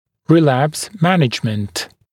[rɪ’læps ‘mænɪʤmənt][ри’лэпс ‘мэниджмэнт]лечение рецидива